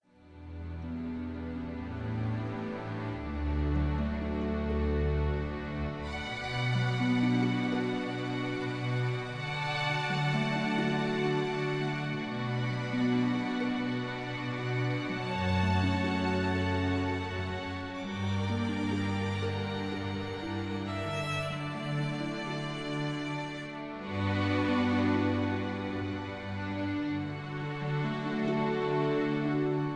(Key-D, Tono de D)
mp3 backing tracks